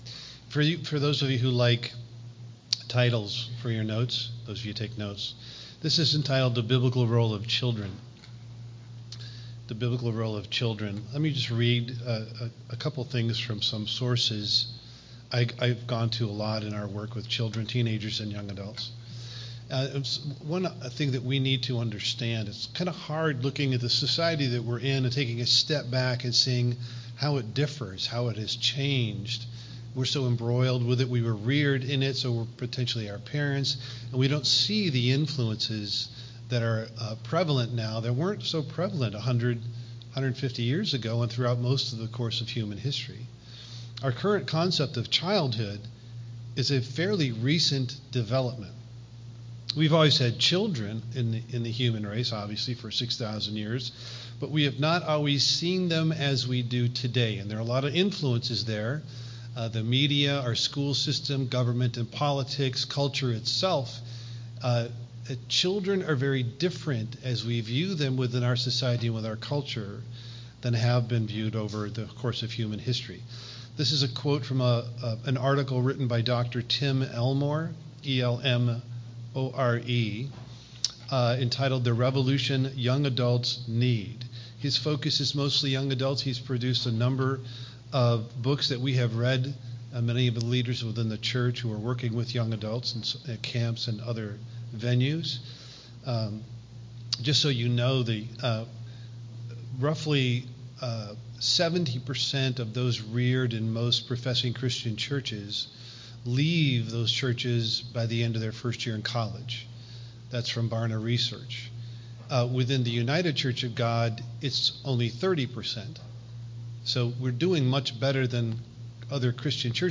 This sermon explores the changing views of childhood throughout history and emphasizes the biblical responsibilities of children in a modern context.